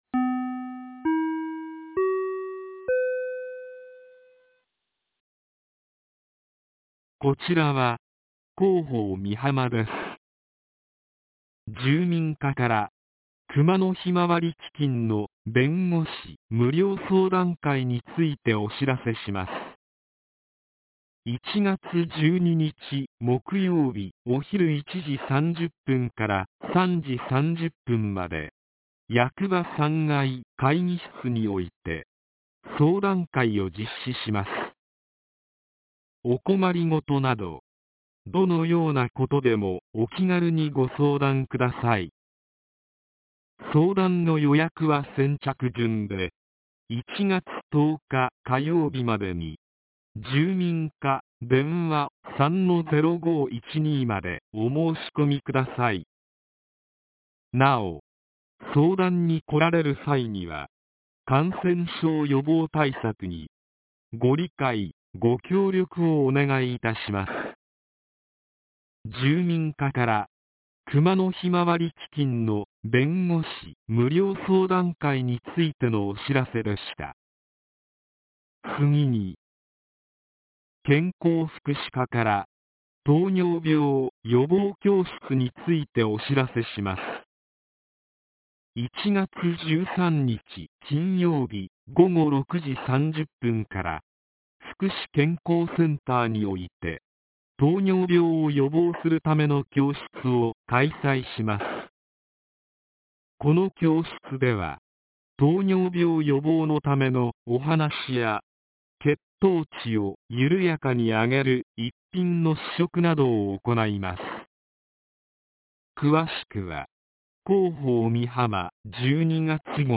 放送音声
■防災行政無線情報■